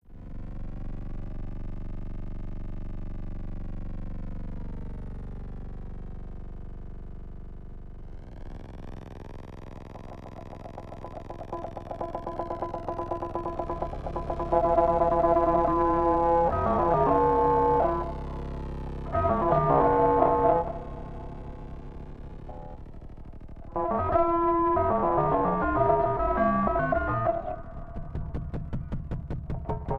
Electric Guitar and SuperCollider
Electronics